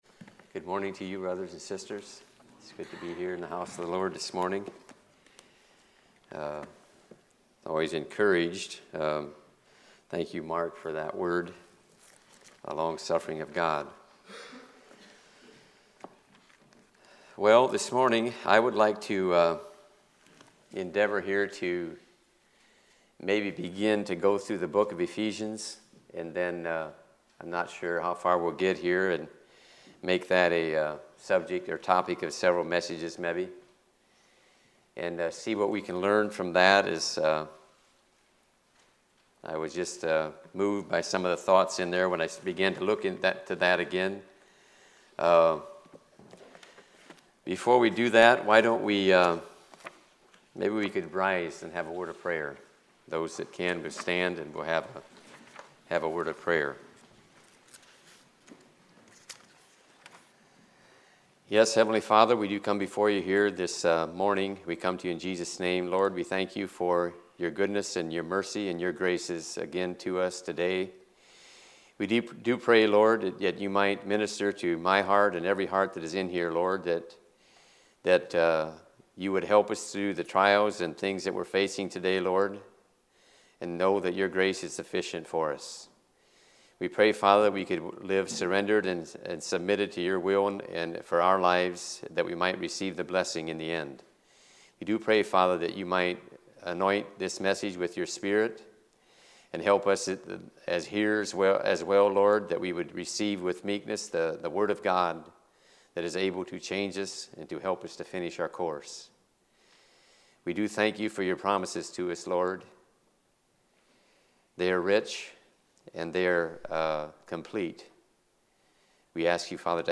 Sunday Morning Sermons